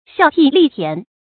孝悌力田 xiào tì lì tián
孝悌力田发音